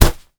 kick_soft_jab_impact_06.wav